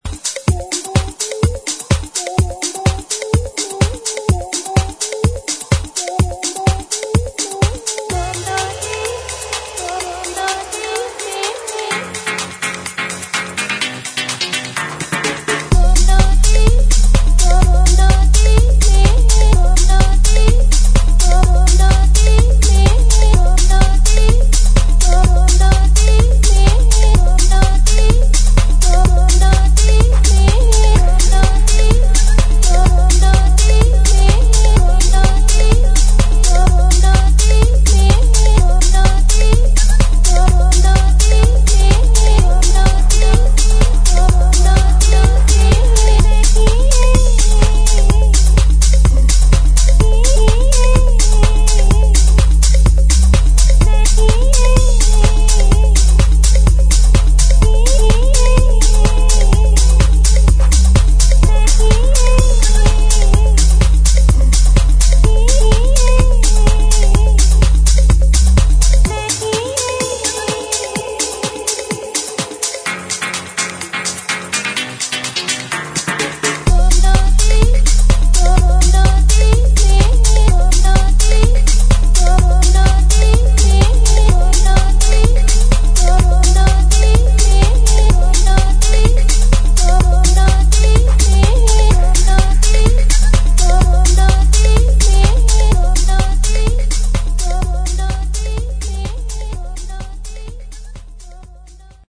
[ HOUSE | DISCO ]